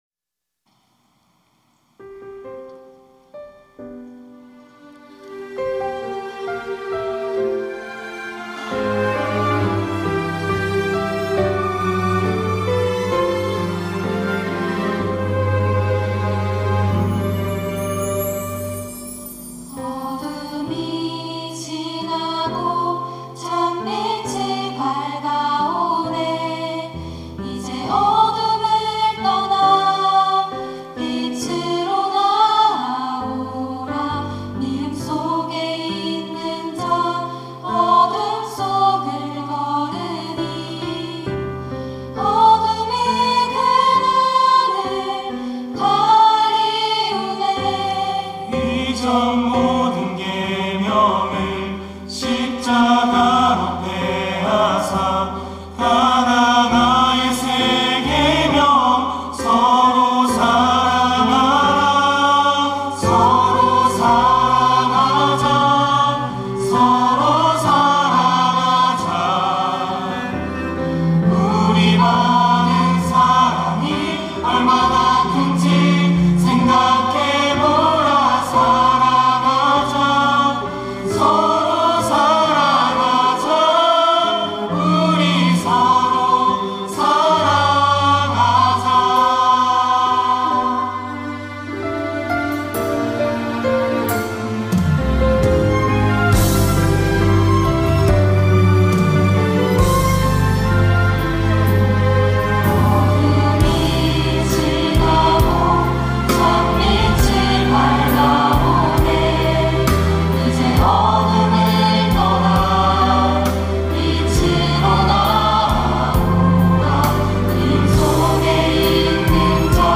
특송과 특주 - 서로 사랑하자
청년부 임원, 교역자, 팀장, 간사, 교사